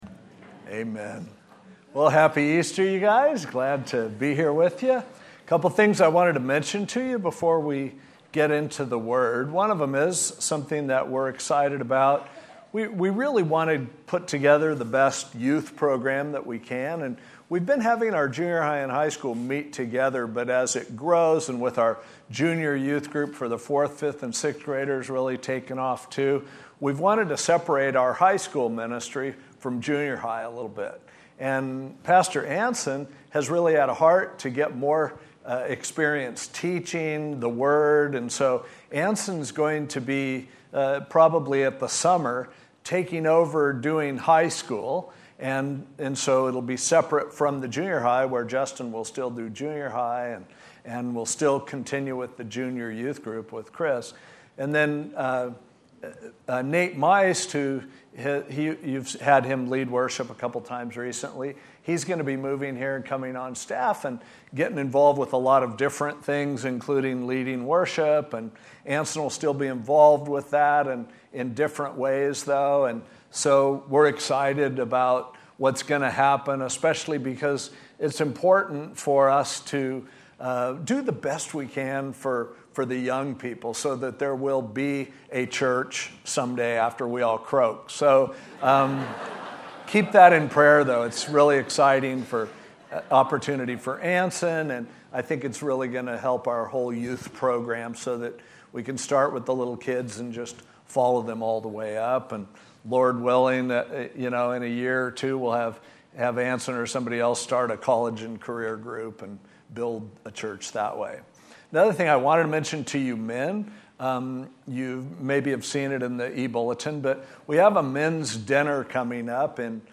A message from the series "The Questions of Jesus."